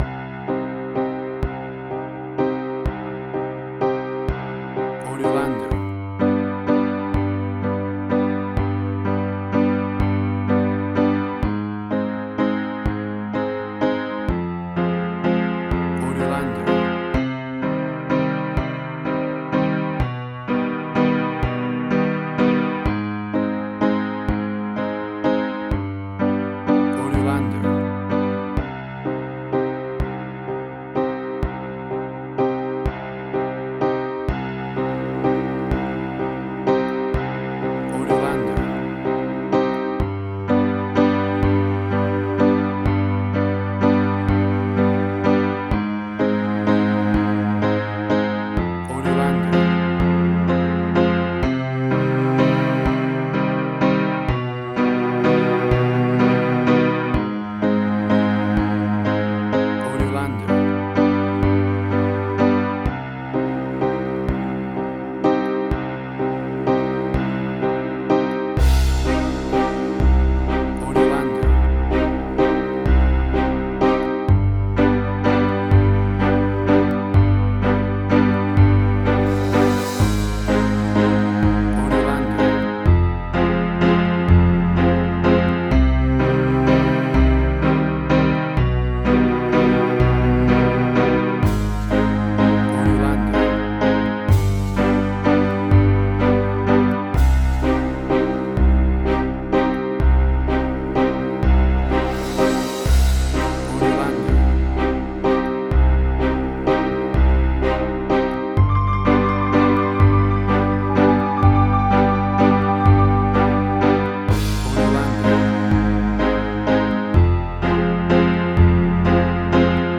Spaghetti Western
WAV Sample Rate: 16-Bit stereo, 44.1 kHz
Tempo (BPM): 42